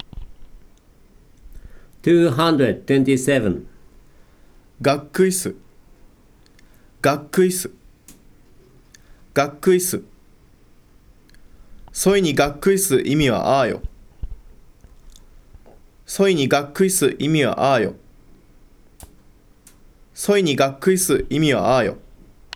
If you click the word in a celll in the table, then you can hear the `non-past' form of the verb and a sentence containing the `non-past' form as the verb of the adnominal clause in Saga western dialect.
227. /gakkui suru/ `get disappointed'